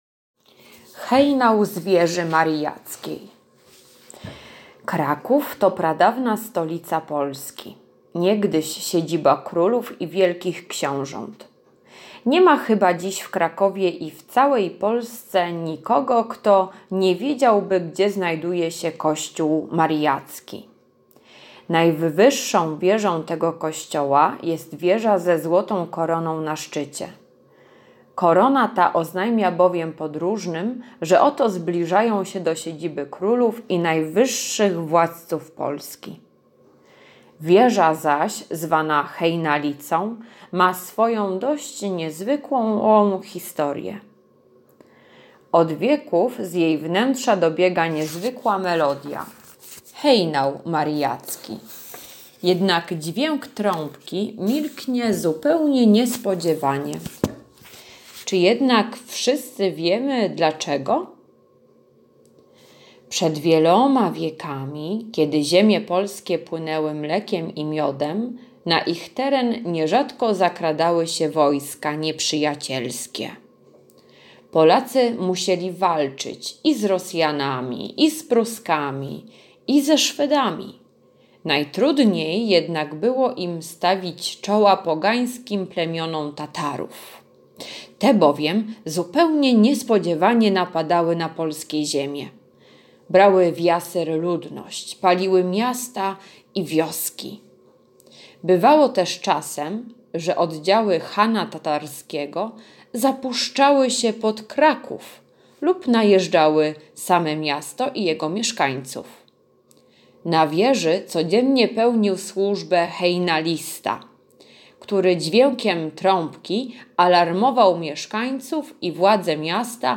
czwartek- legenda dla chętnych [2.91 MB] czwartek- ćw. dla chętnych [743.97 kB] czwartek- ćw. dla chętnych [763.70 kB]